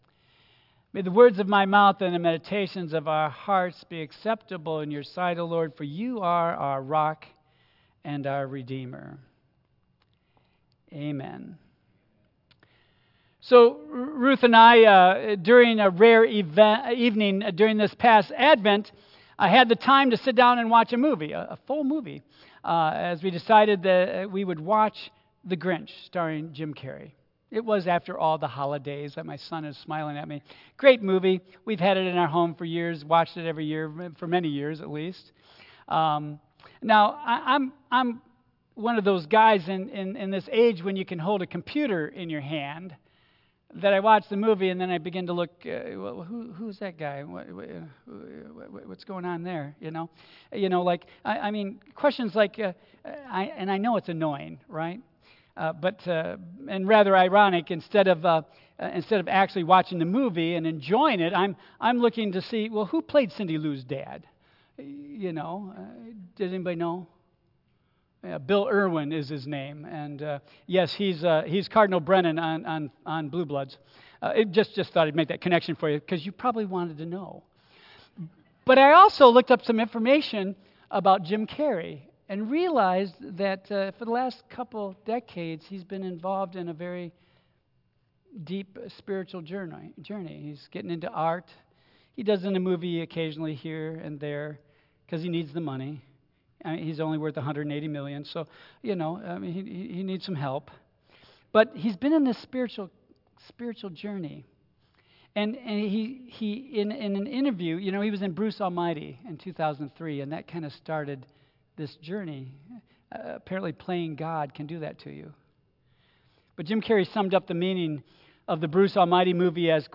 Tagged with Michigan , Sermon , Waterford Central United Methodist Church , Worship Audio (MP3) 8 MB Previous When Sea Billows Roll Next The Attitude of Compassion